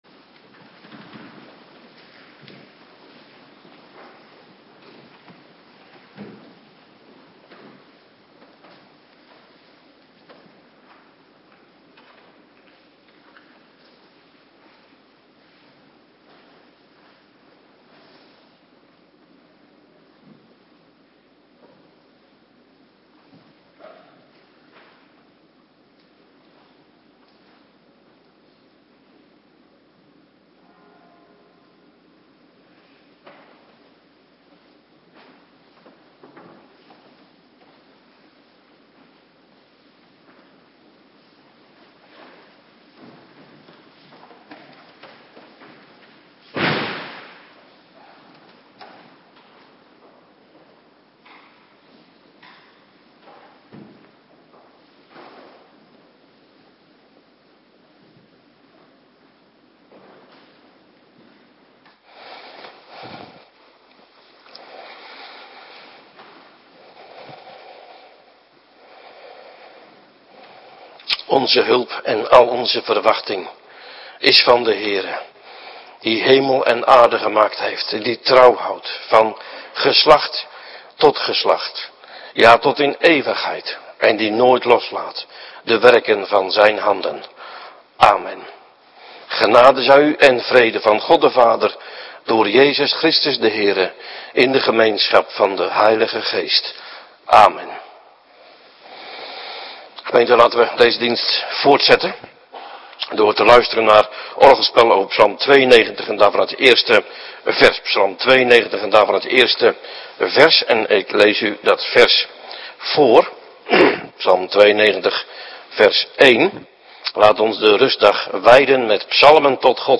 Avonddienst bediening Heilige Doop - Cluster 2
Locatie: Hervormde Gemeente Waarder